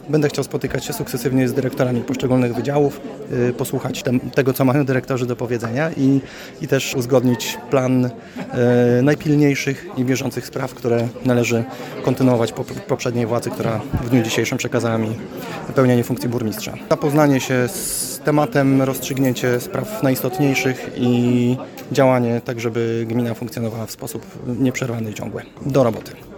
Podczas pierwszej w tej kadencji sesji Rady Miejskiej złożył on ślubowanie i odebrał akt nominacji na stanowisko. W pierwszej kolejności, jak sam mówi, chce spotkać się z pracownikami magistratu.